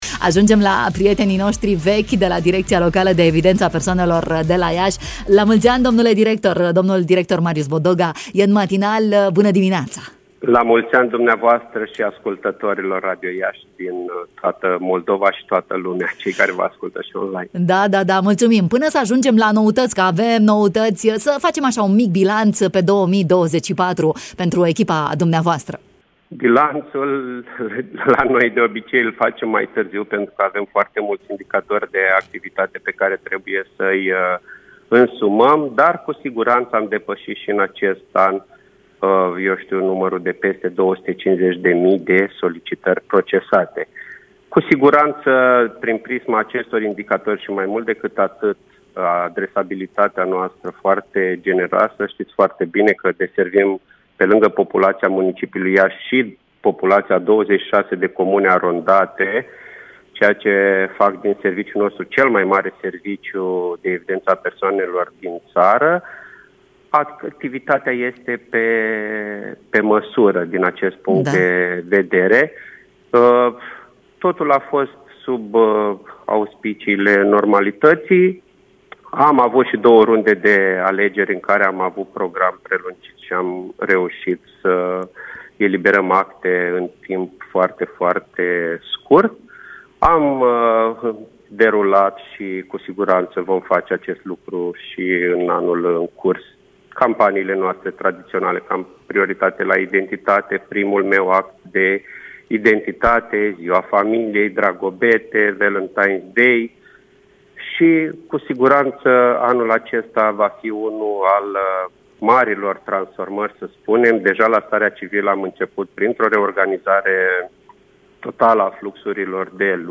în direct